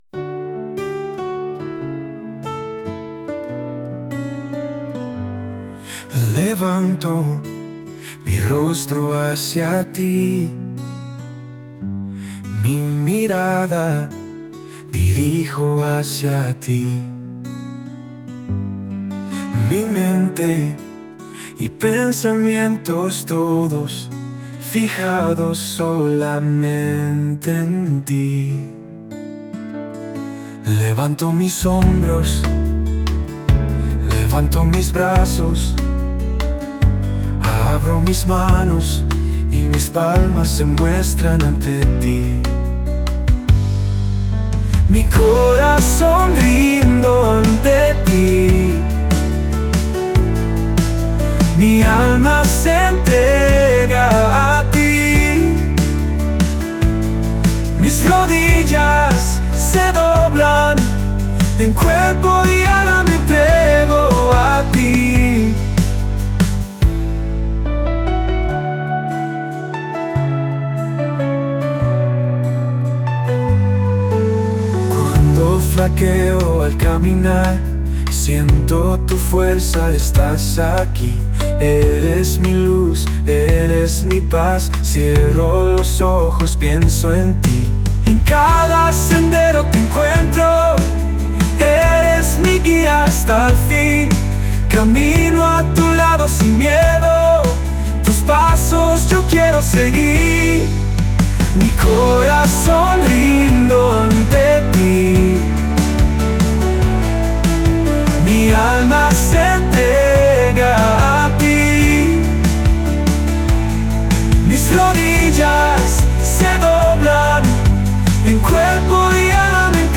Rock Suave